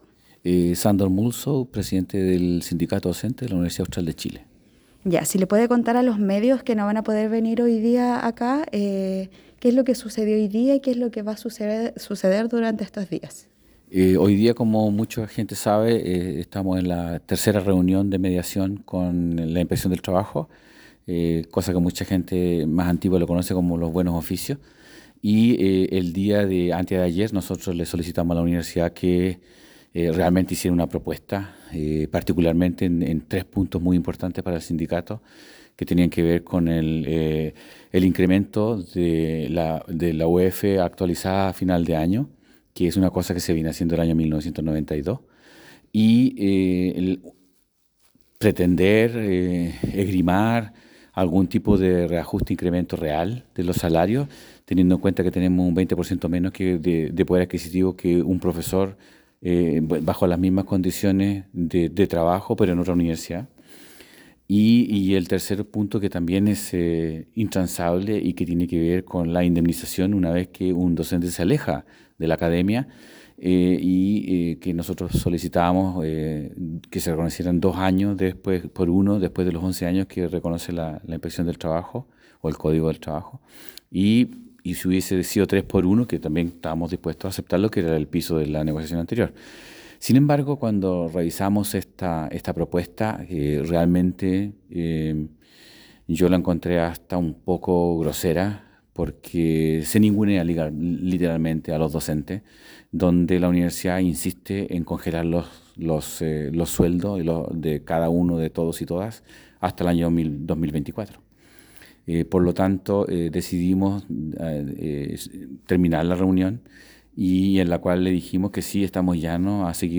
Con el objetivo de dar a conocer su postura respecto a la última propuesta de contrato colectivo presentado por la Universidad, el Sindicato de Docentes realizó este viernes un punto de prensa. En el dieron a conocer que consideraban inaceptable la última propuesta de la UACh, ya que precariza las condiciones laborales de sus docentes.